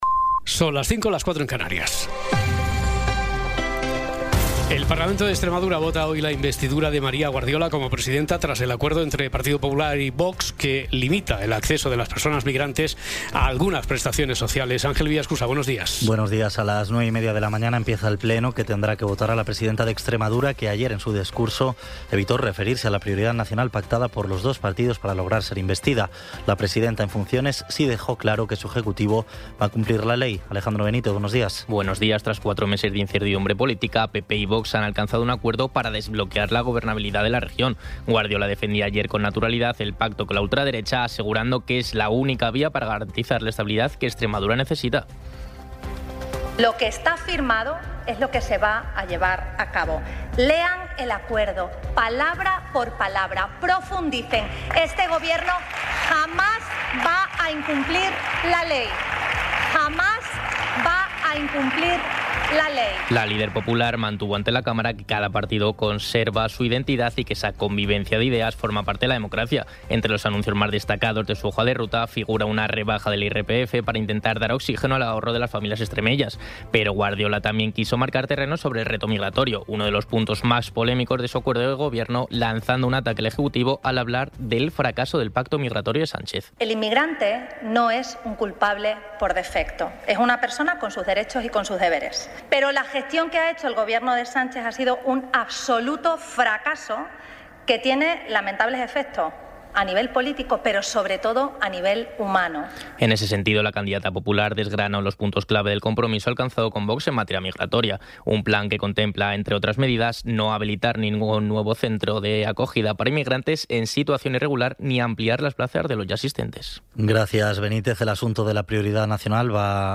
Resumen informativo con las noticias más destacadas del 22 de abril de 2026 a las cinco de la mañana.